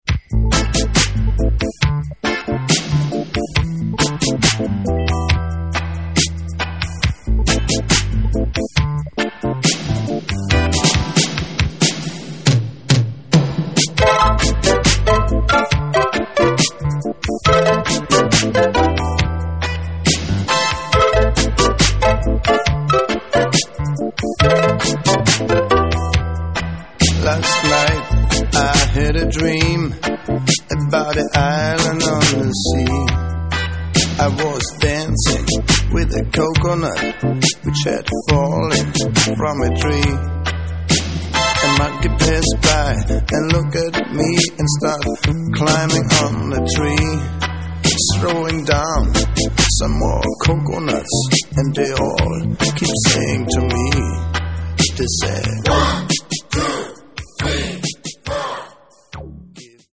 вокал, бас, перкуссия, клавишные